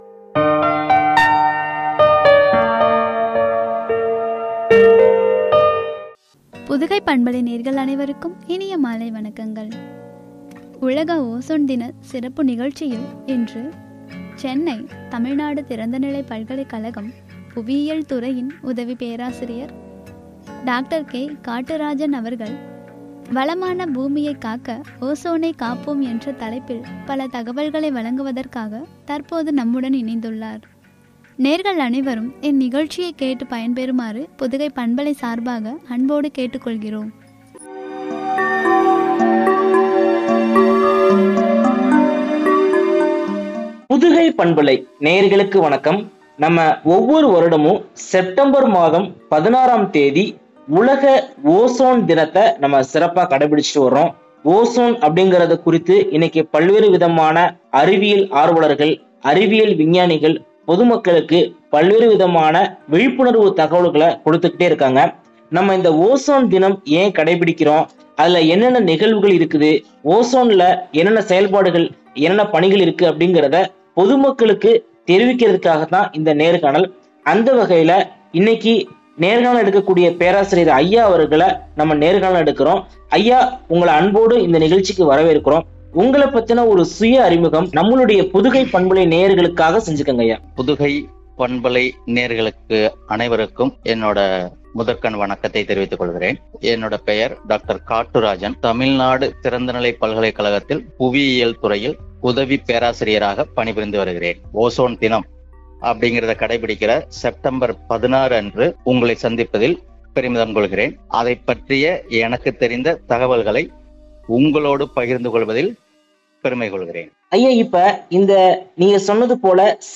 ஓசோனை காப்போம்” எனும் தலைப்பில் வழங்கிய உரையாடல்.